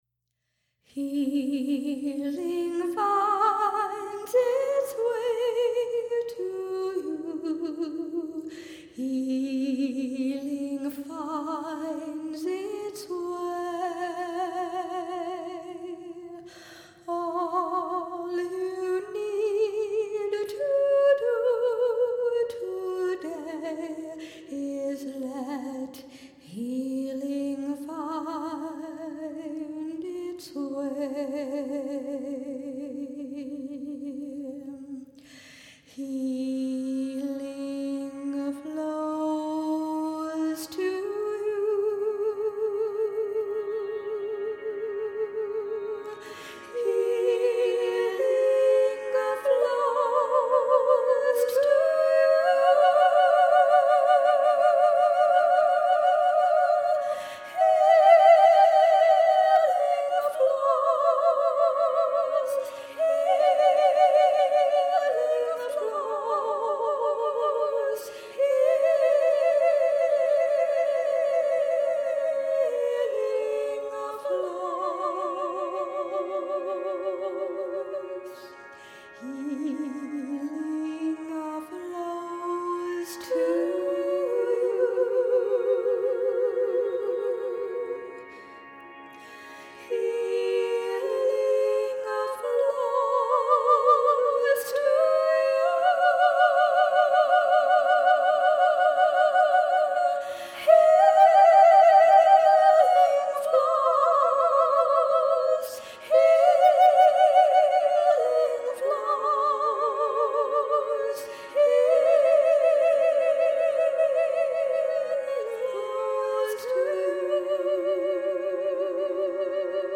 Soundscape and keyboards